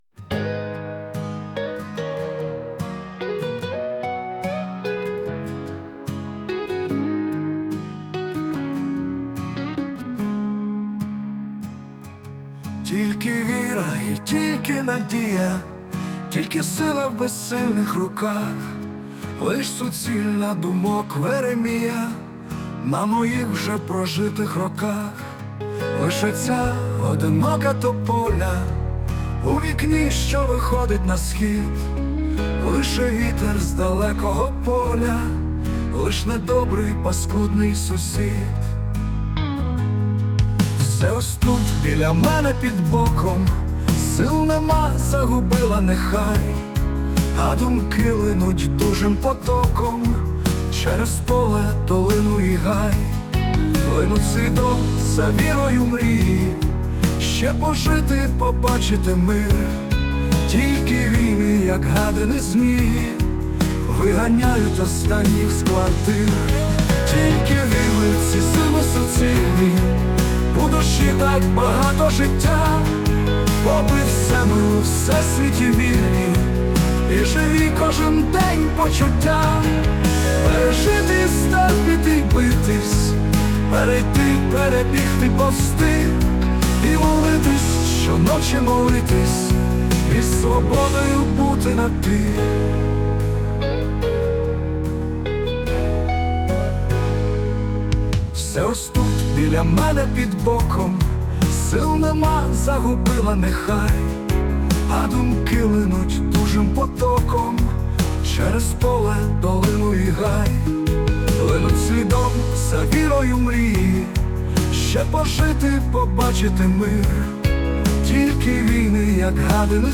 Створено за допомогою штучного інтелекту